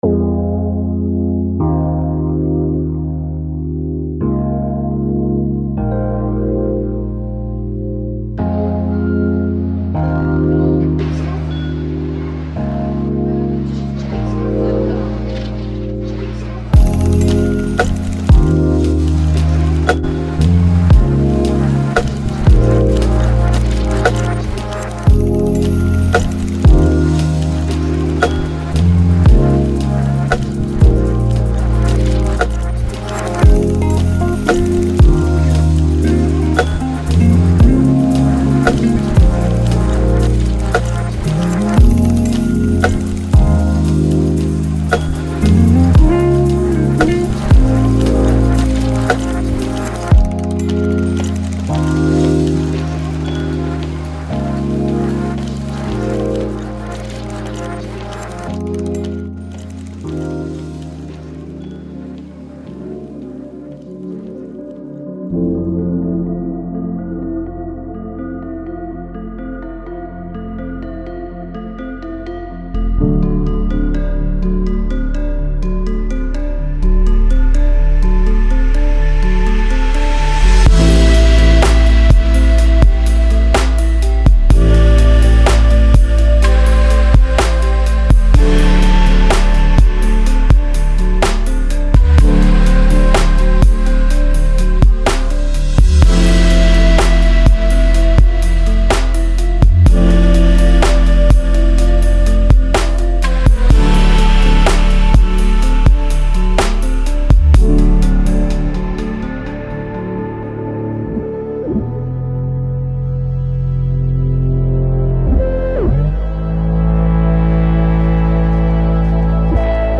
充满了空灵的和弦进行曲，闪闪发光的旋律，不断变化的低音，清晰的鼓声，复杂的foley FX等。
精选的11个鼓loop环为庇护所提供了温暖的模拟床，是由活的和合成的元素精心制作而成的。
• 10个温暖的低音循环（包括Midi文件）
• 19个滑行的旋律循环（包括Midi文件）
• 节奏-115，125BPM